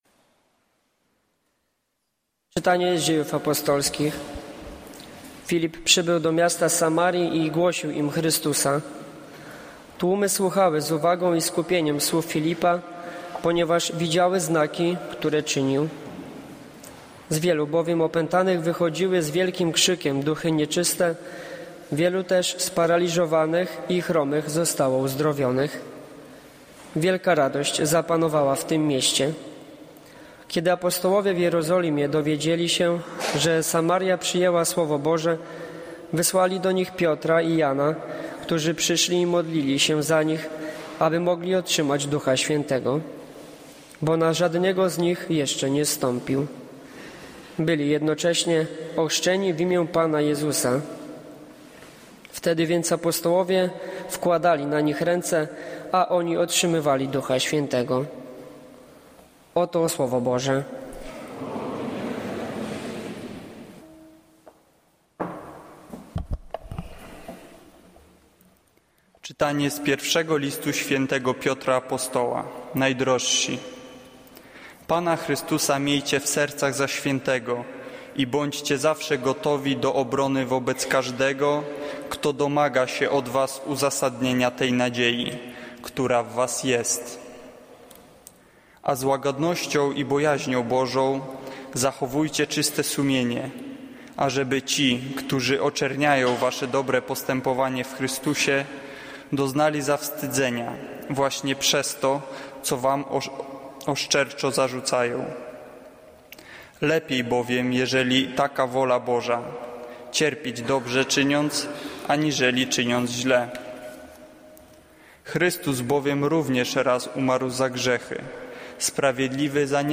Kazanie z 12 kwietnia 2015r.